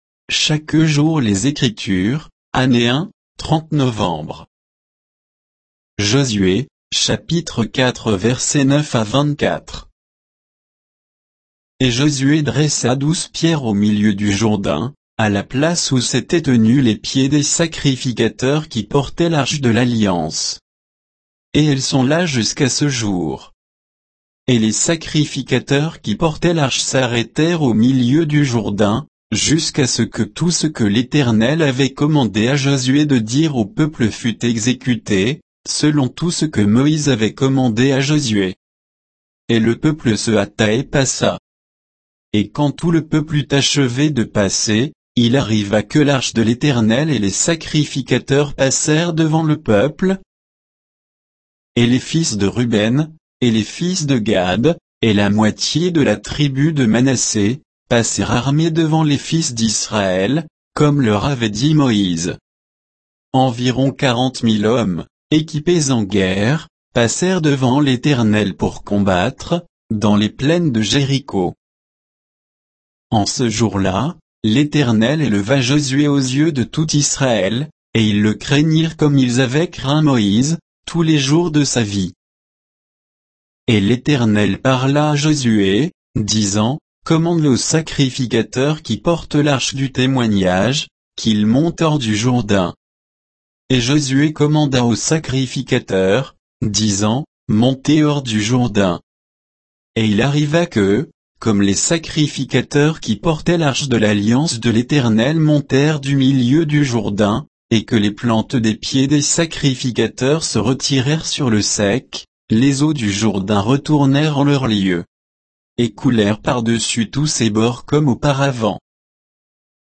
Méditation quoditienne de Chaque jour les Écritures sur Josué 4, 9 à 24